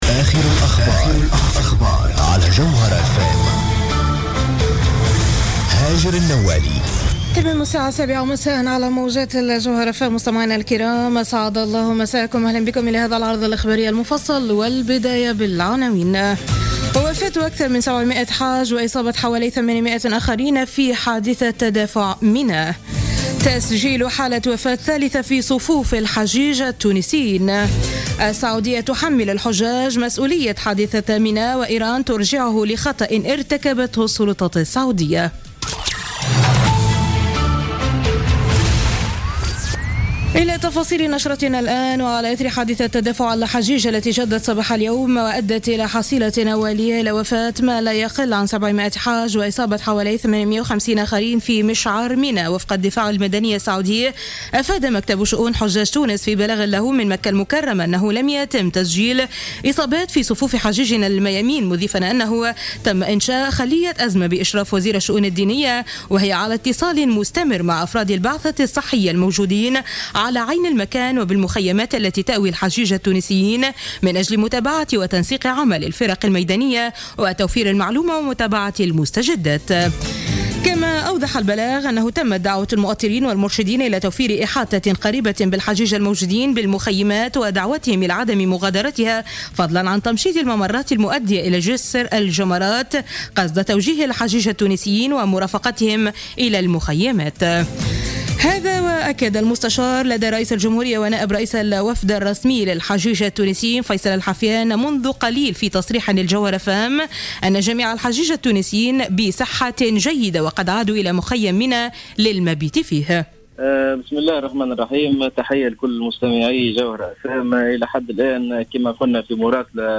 نشرة أخبار السابعة مساء ليوم الخميس 24 سبتمبر 2015